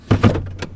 GearShifting4.WAV